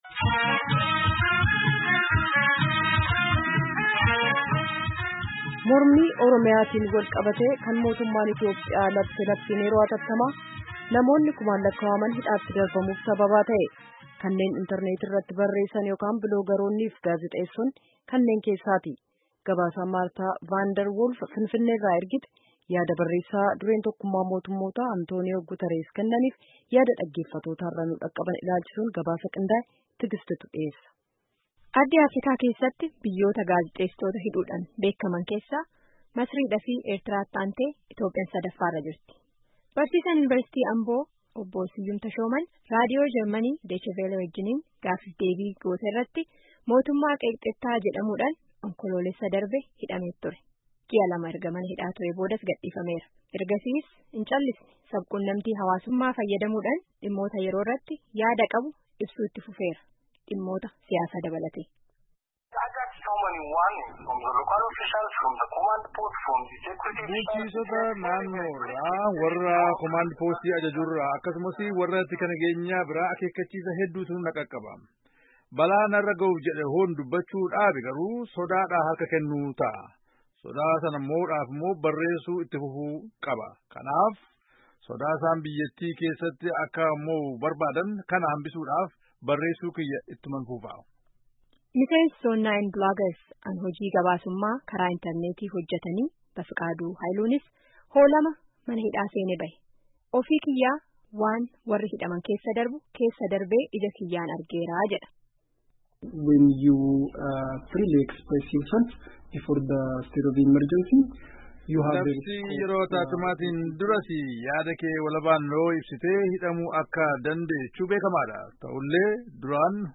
Mormii Oromiyaatiin booda labsiin yeroo atattaamaa mootummaan Itoophiyaa labse namoota kumaan lakkaawwaman hidhaati naqsiiseera. Gaazexesitoonnii fi barreesitoonni ammi tokkos to’annaa jala olfamanii turan. Kanneen hidhaa dha bahan keessaa Raadiyoonii Sagalee Ameerikaa wajjin gaafii fi debii an...